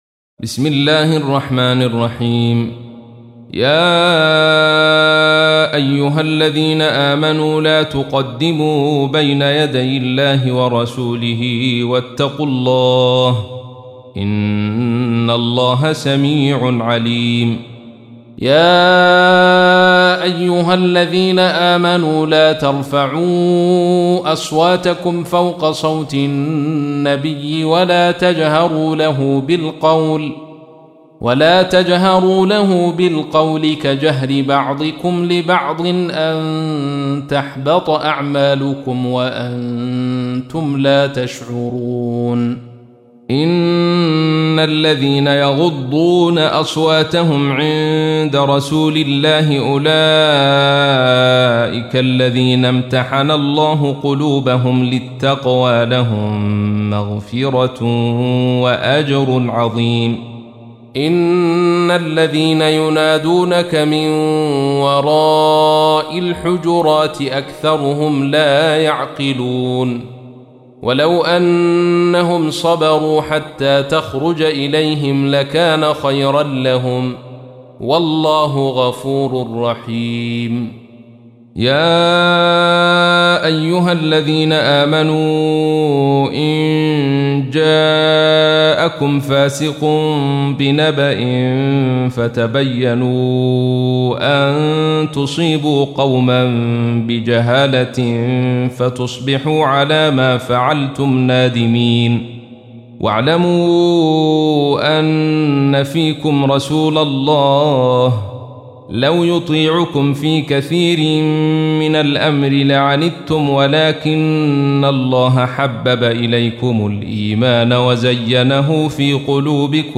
تحميل : 49. سورة الحجرات / القارئ عبد الرشيد صوفي / القرآن الكريم / موقع يا حسين